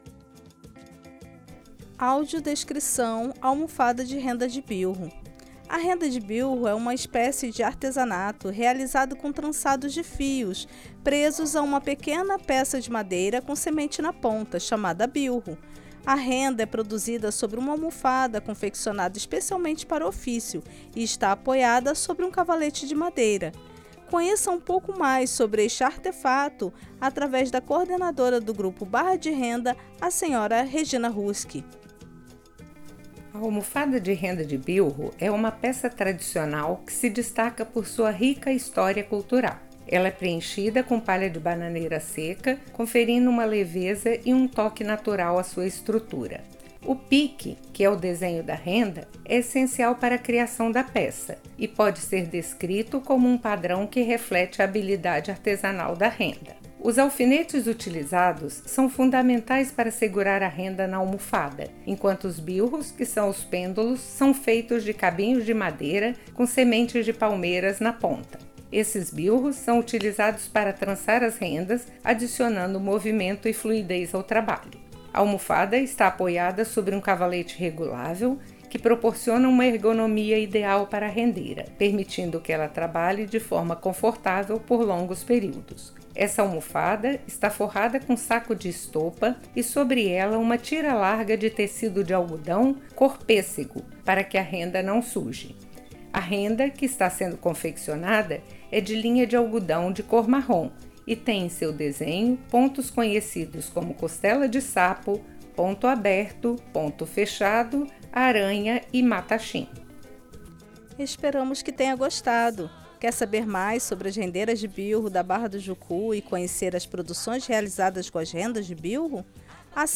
Audiodescrição Almofada de Renda de Bilro
AUDIO-DESCRICAO-ALMOFADA-DE-RENDA-DE-BILRO.mp3